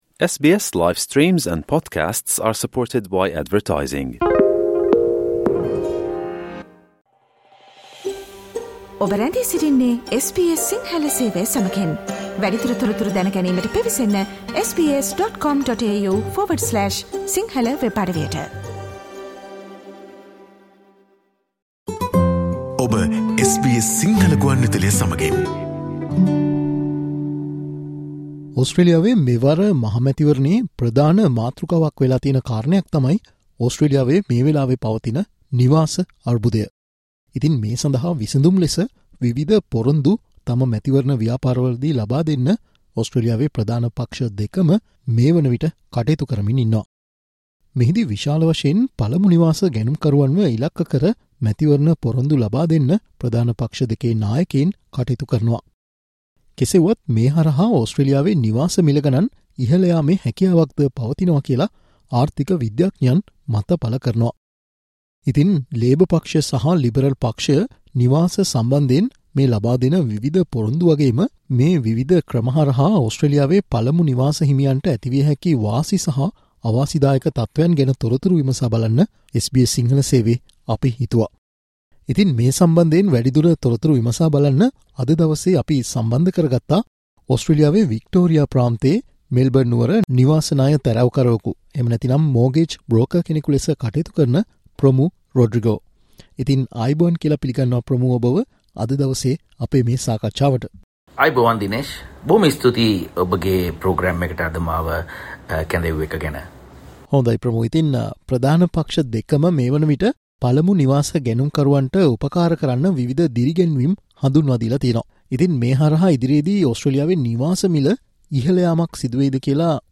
විශේෂයෙන්ම පළමු නිවාස ගැනුම් කරුවන් ඉලක්ක කර විශේෂ සහන ලබාදෙන්න මේ ප්‍රධාන පක්ෂ දෙකම කටයුතු කර තිබෙනවා. මේ මැතිවරණ පොරොන්දු වල ඇති වාසි අවාසි පිළිබඳව SBS සිංහල සේවය සිදුකල සාකච්චාවට සවන් දෙන්න